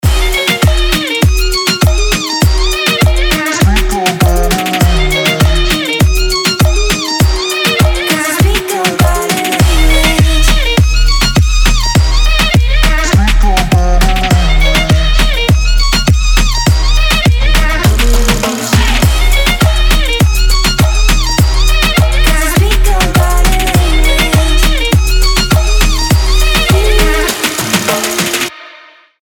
Ритмичный club house